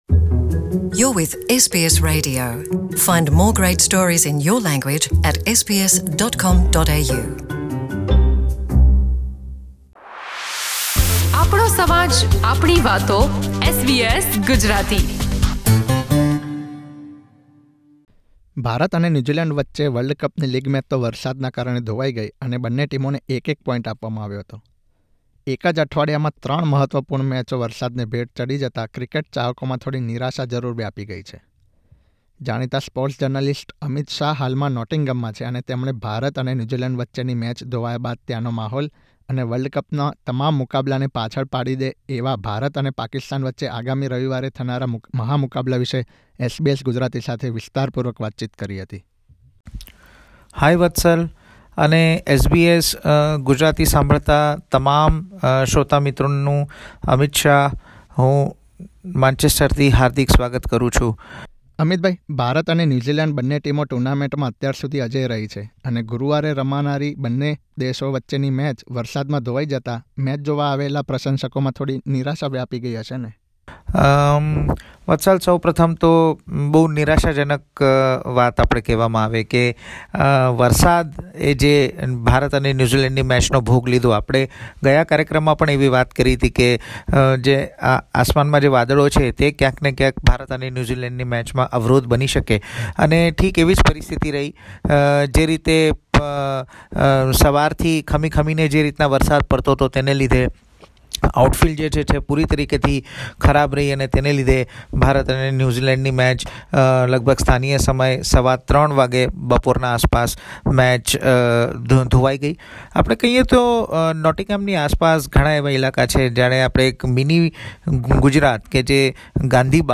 a sports journalist from England, talks about the weather conditions and about the preparation by both teams for the most awaited clash.